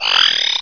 1 channel
pain2.wav